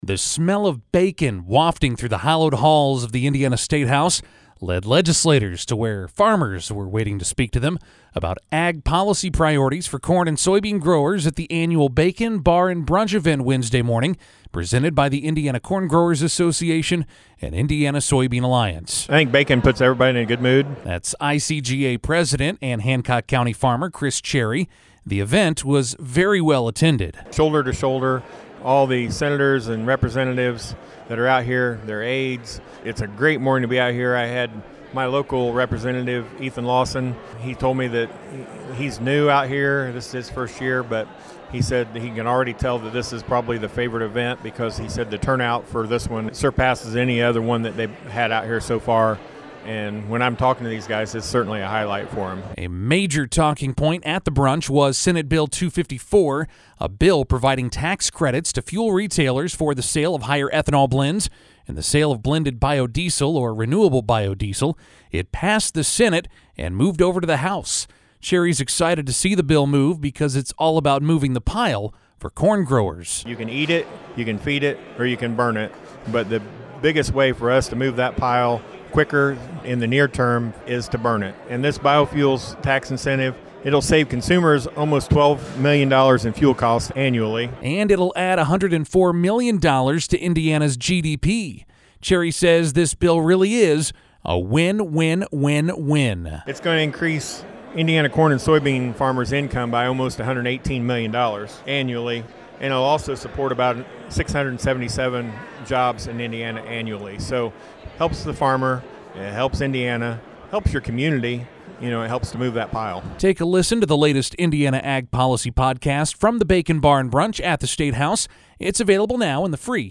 The smell of bacon wafting through the hallowed halls of the Indiana Statehouse led legislators to where farmers were waiting to speak to them about ag policy priorities for corn and soybean growers at the annual Bacon Bar & Brunch event Wednesday morning, presented by the Indiana Corn Growers Association and Indiana Soybean Alliance.